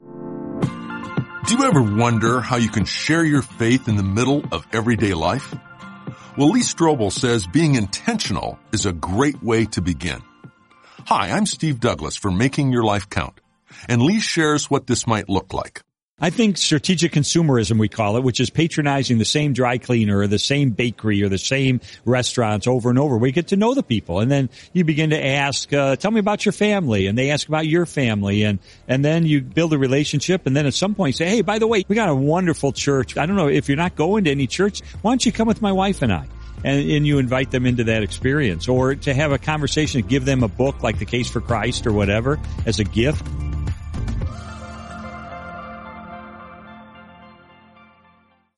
Author and speaker Lee Strobel explains a great way to do this.